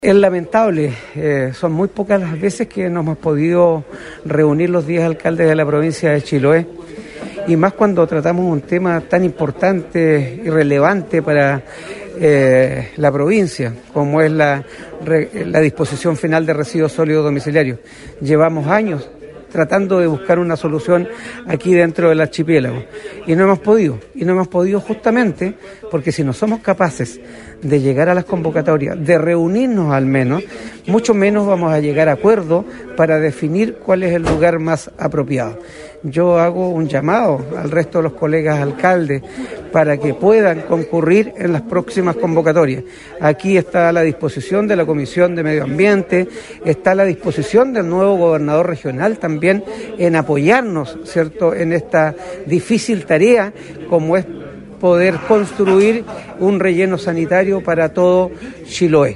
A su vez, el alcalde de Ancud, Carlos Gómez, también recalcó la importancia de la asistencia de todos los alcaldes, pues se trata de una problemática de carácter provincial.
11-CARLOS-GOMEZ-ANCUD.mp3